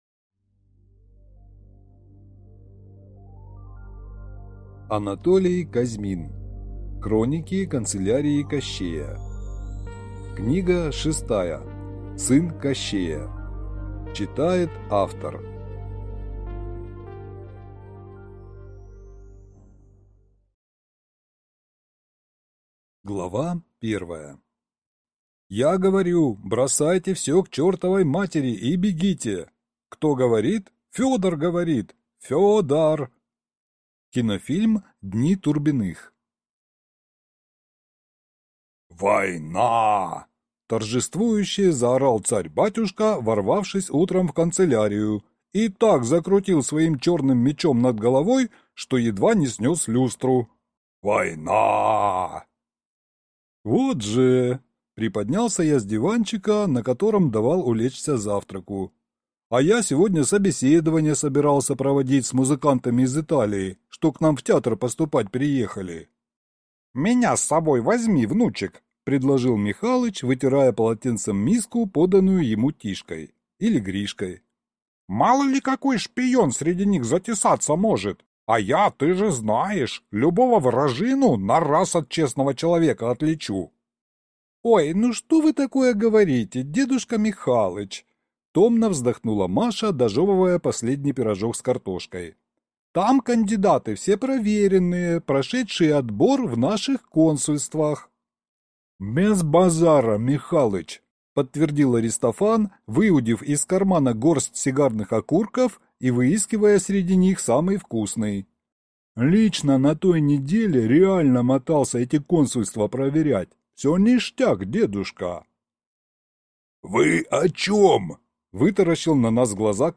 Аудиокнига Сын Кощея | Библиотека аудиокниг